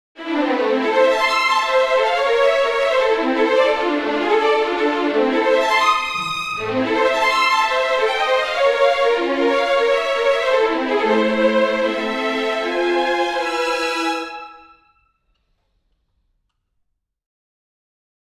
тест быстрых струн